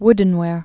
(wdn-wâr)